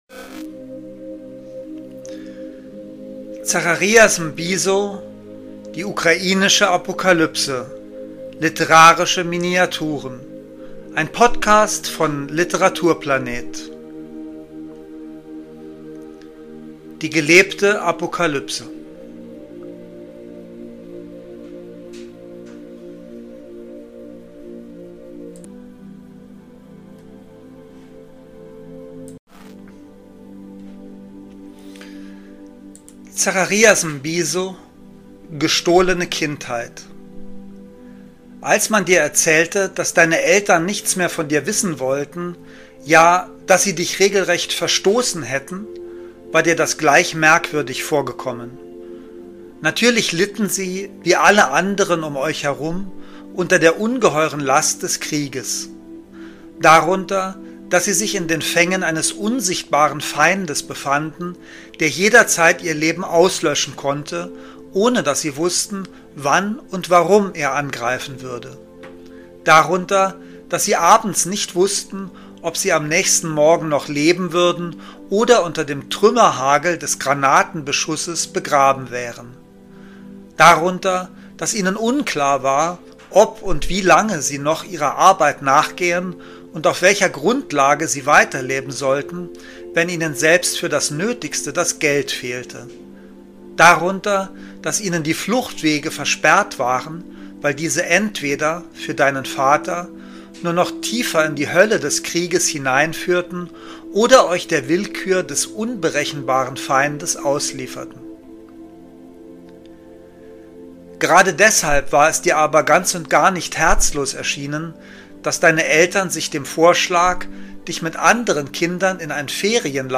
Lesungen: Literarische Miniaturen